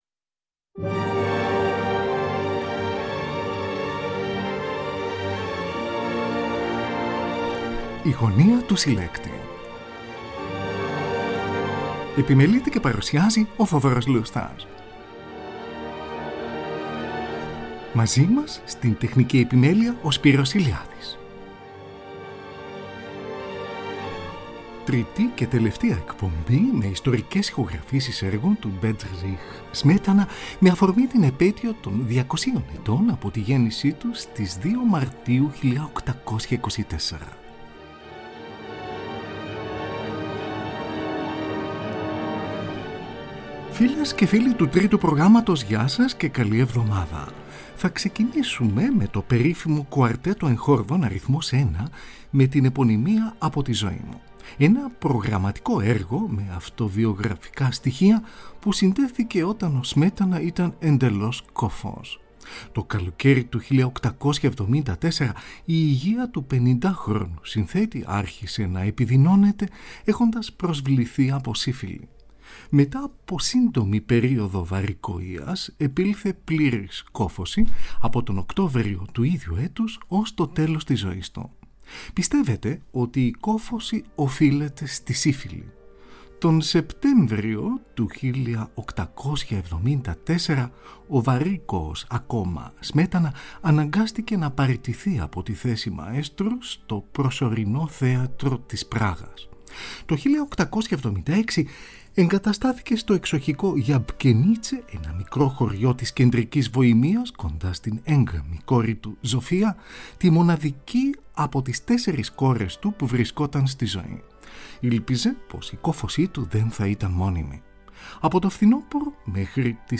Andantino σε σολ ελάσσονα, δεύτερο κομμάτι της δίπτυχης συλλογής για βιολί και πιάνο, με γενικό τίτλο «Από την πατρίδα» .
από ζωντανή ηχογράφηση